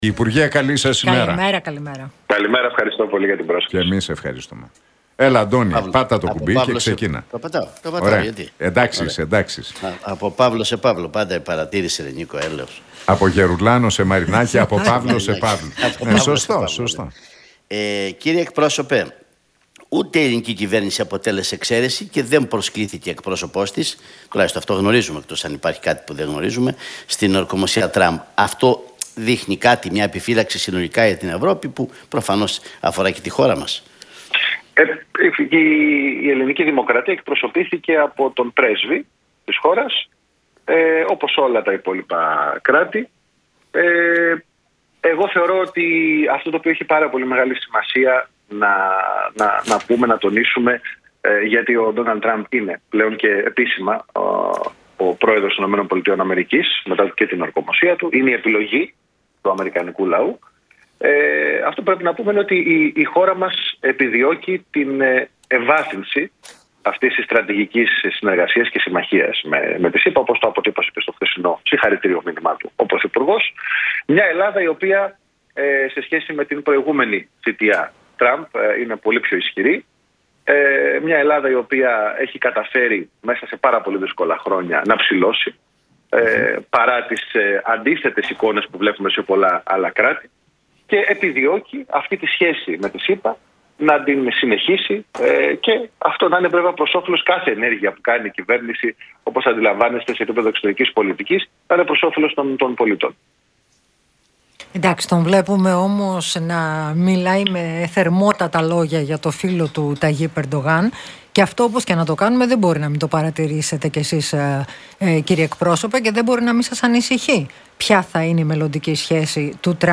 μίλησε ο υφυπουργός παρά τω πρωθυπουργώ και κυβερνητικός εκπρόσωπος, Παύλος Μαρινάκης
από την συχνότητα του Realfm 97,8.